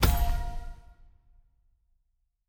Special Click 05.wav